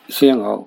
Cdo-fzho_45_(siék-ngô).ogg